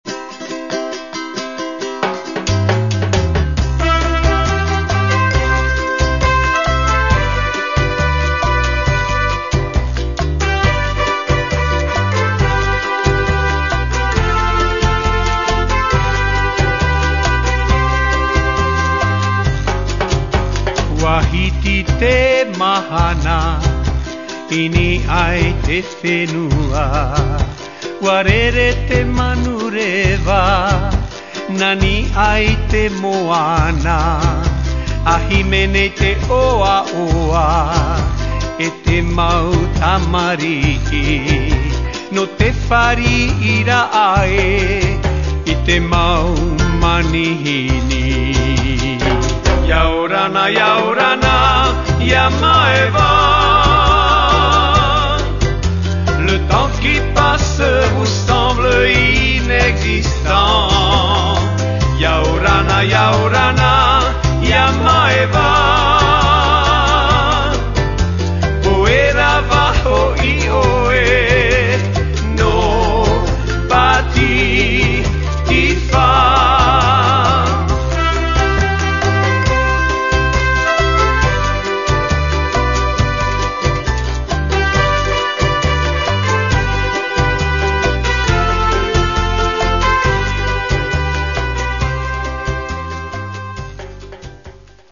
Musique Tahitienne